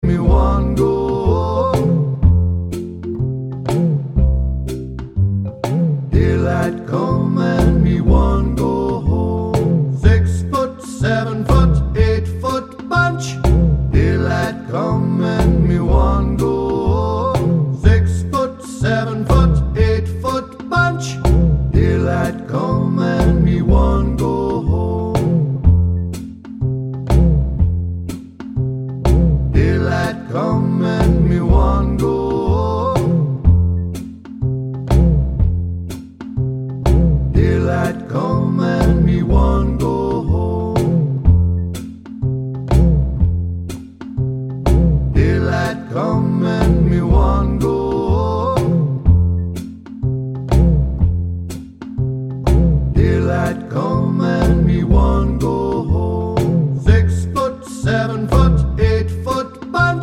no Backing Vocals Soundtracks 3:04 Buy £1.50